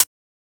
Closed Hats
edm-hihat-59.wav